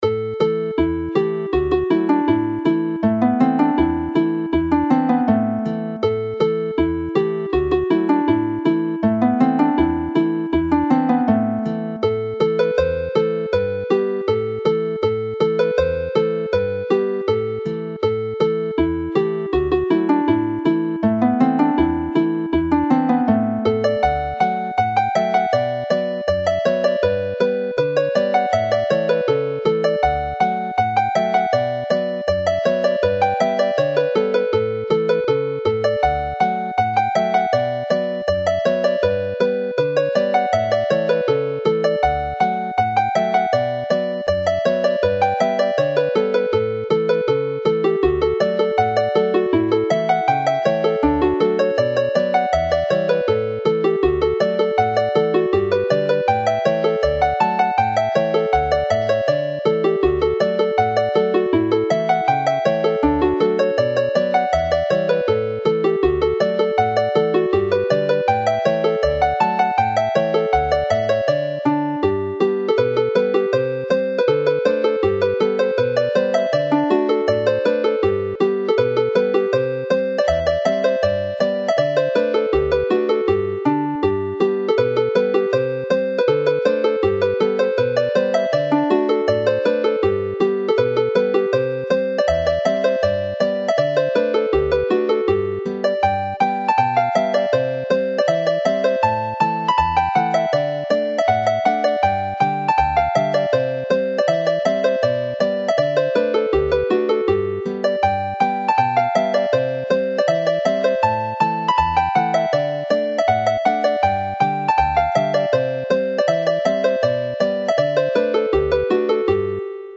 Alawon Cymreig - Set Dowlais - Welsh folk tunes to play